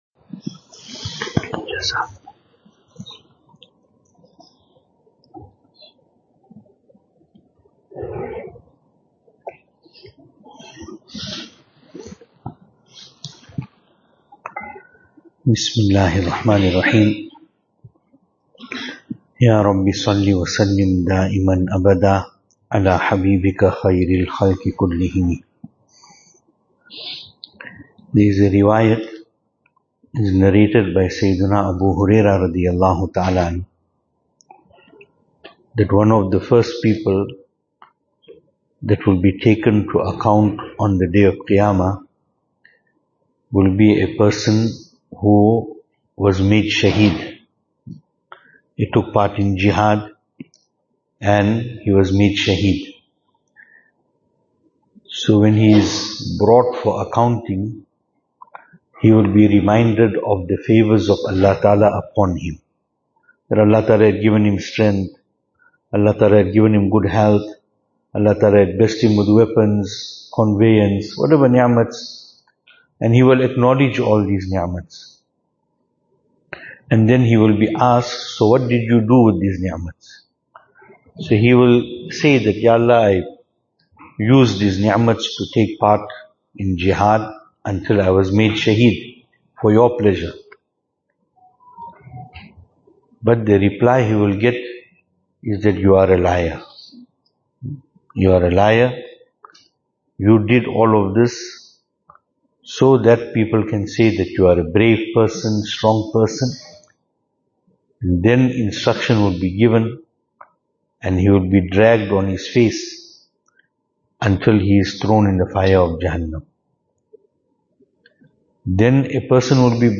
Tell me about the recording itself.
Programme from Stanger Jaamia Musjid 2025-11-11 Programme from Stanger Jaamia Musjid Venue: Stanger Jaamia Musjid Service Type: Out-Program « Importance of Ilm e Deen Love for Allah Ta’ala is the solution to all situations we may face .